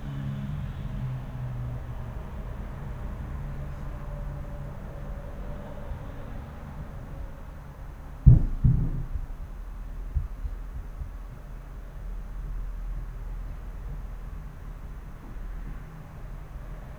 06.12.2014, 17.44 Uhr 10 sec ein Ereignis, in der der Fernseher etwas unter Zimmerlautstärke lief.
Das Trittschallereignis selber wird wieder durch ein Hochschießen der Kurve nach oben deutlich wie man am Ausschnitt des rechten Graphen sieht.